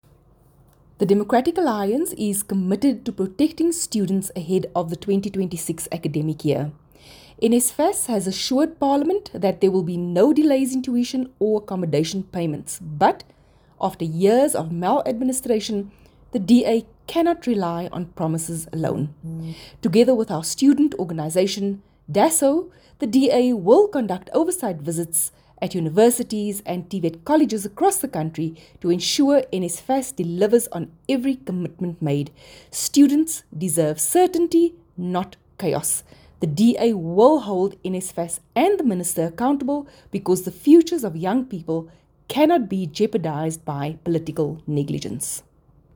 Issued by Dr Delmaine Christians MP – DA Spokesperson on Higher Education and Training
Afrikaans soundbites by Dr Delmaine Christians MP.